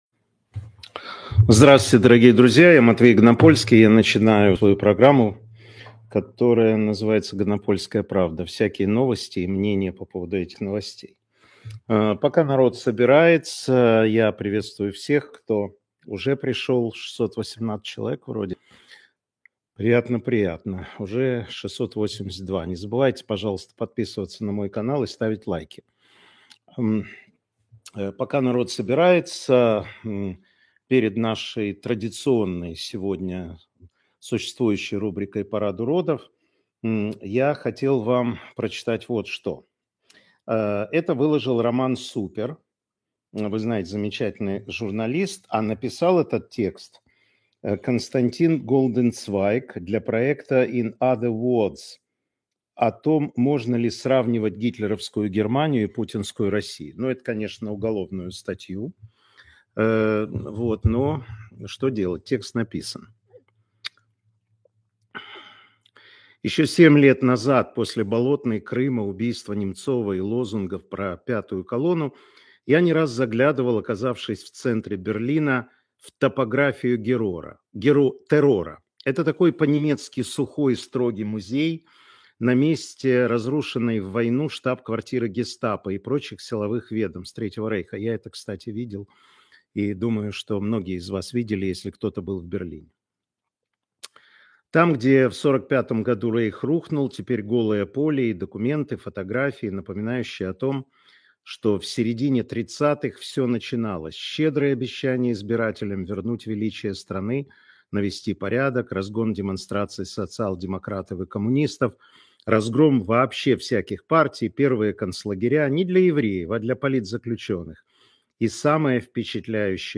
Аналитическая программа Матвея Ганапольского